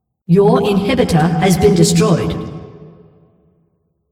yourinhibitor_destroyed.mp3